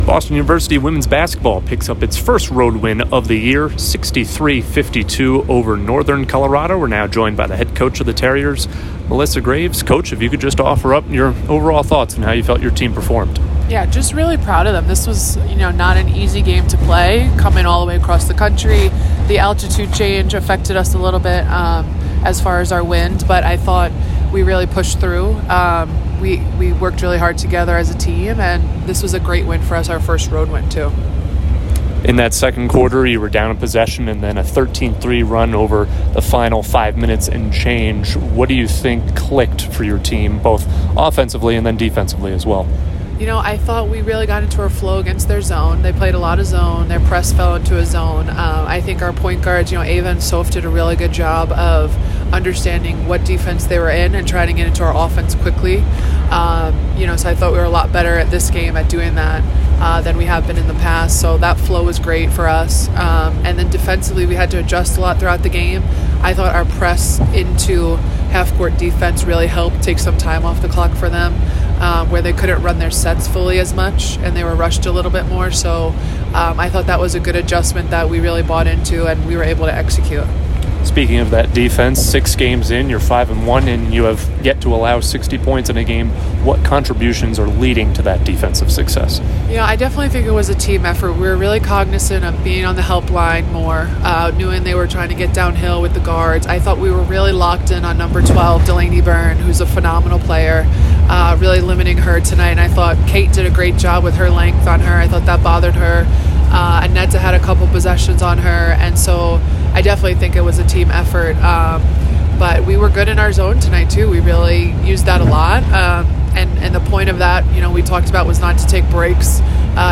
WBB_UNCo_Postgame.mp3